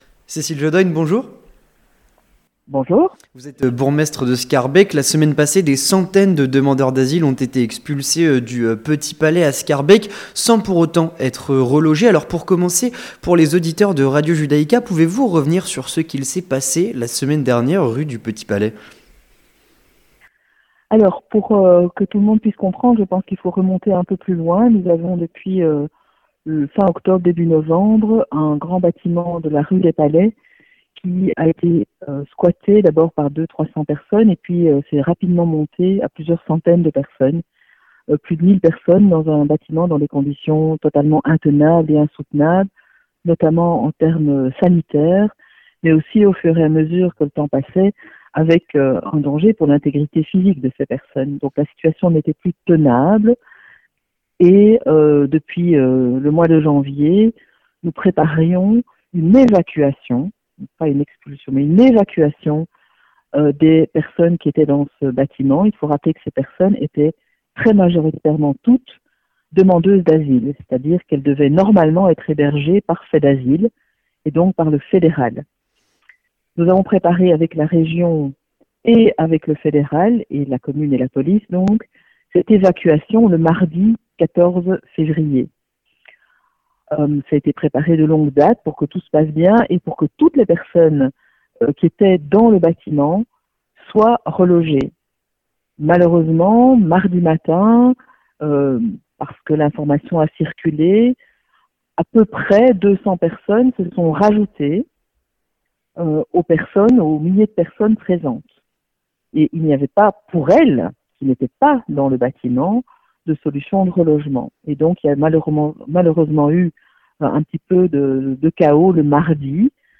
Situation des migrants à Schaerbeek et combat politique enclenché avec Cécile Jodogne, bourgmestre de Schaerbeek